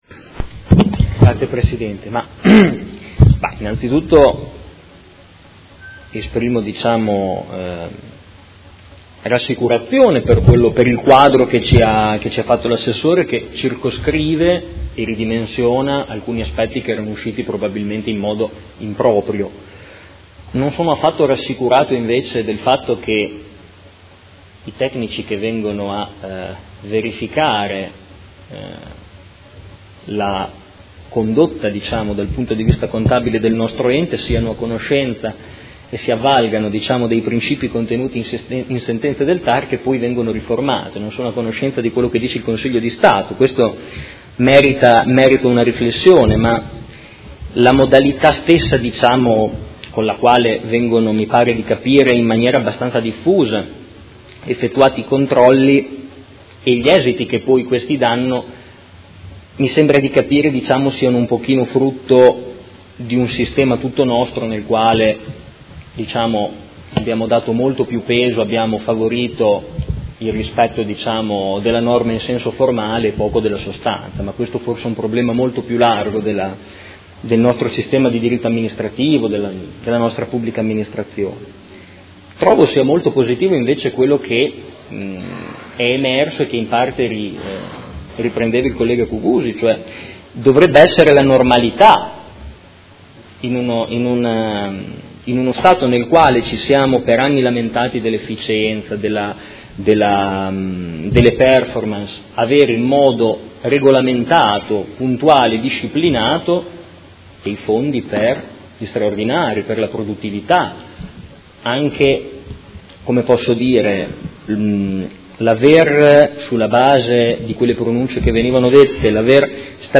Marco Forghieri — Sito Audio Consiglio Comunale
Seduta del 17/05/2018. Dibattito su interrogazione del Gruppo M5S avente per oggetto: Visita ispettiva MEF presso il Comune di Modena